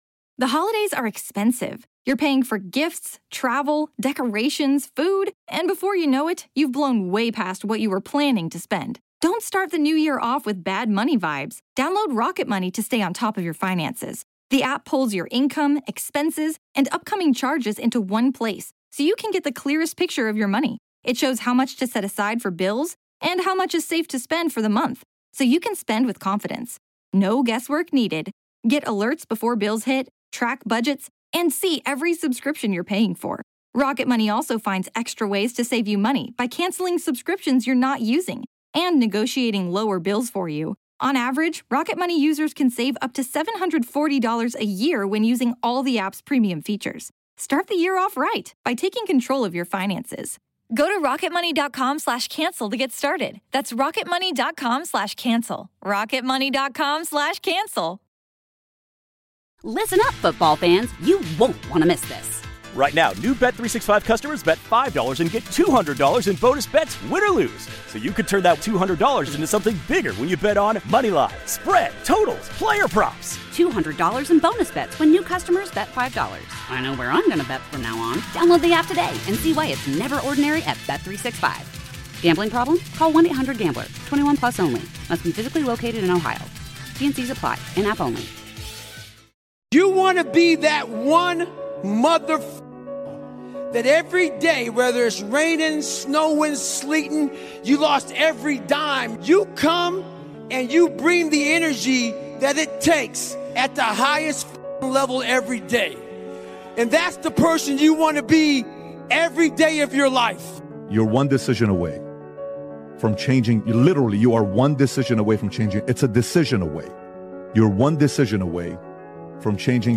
motivational speech